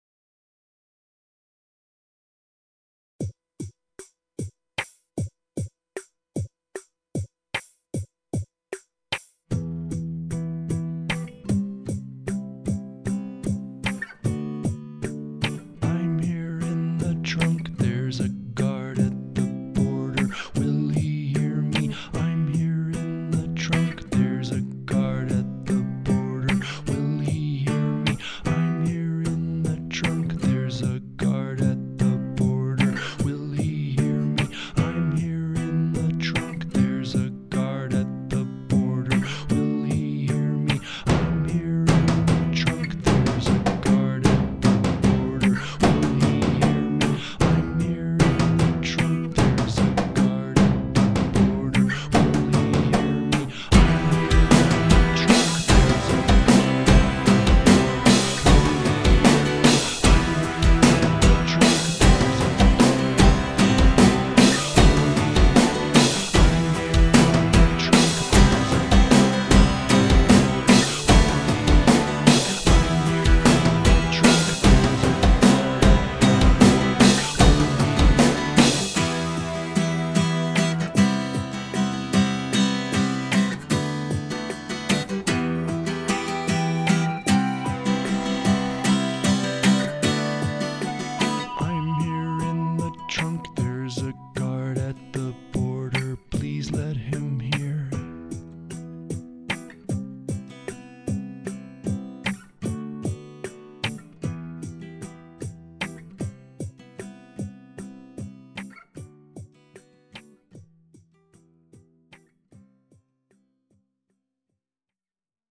Acousticlectic Genrephobic Experimentaotic Sound